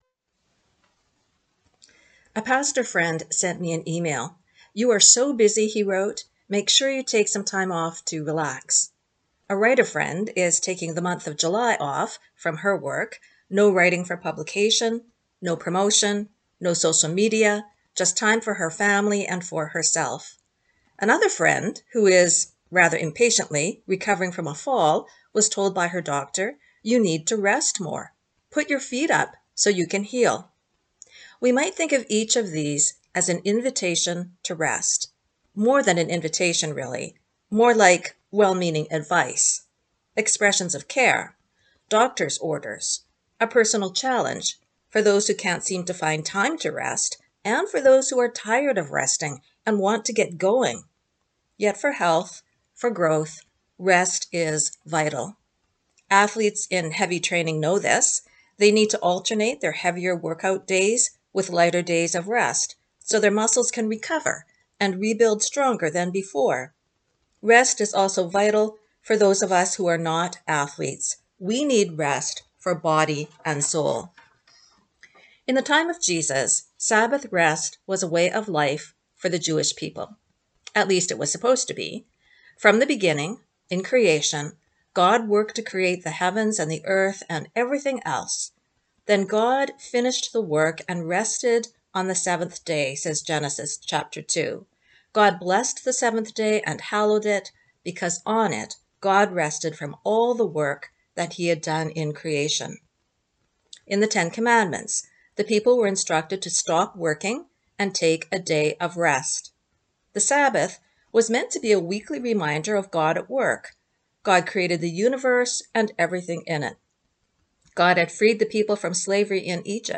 In my church, the “sermon” is generally a short homily of 10-15 minutes focused on one of the four lectionary Scripture readings for that Sunday.